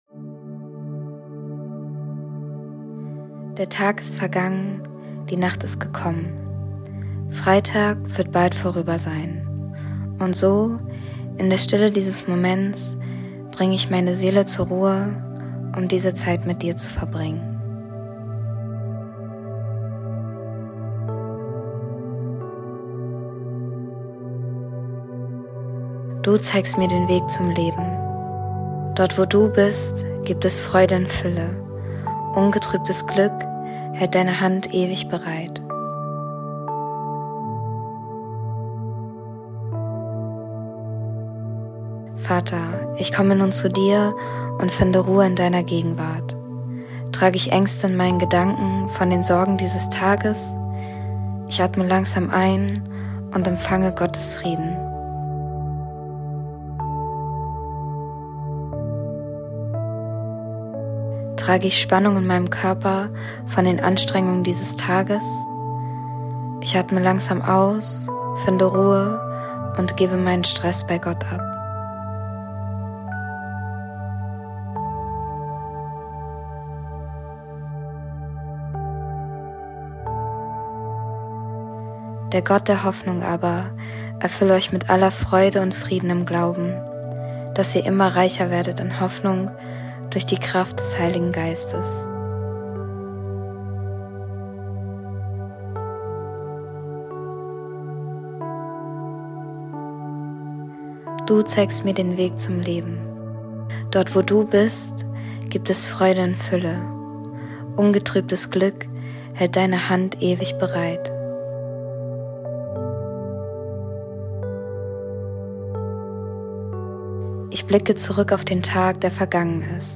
Abendmeditation am 01.09.23